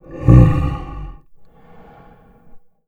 MONSTER_Growl_Breath_01_mono.wav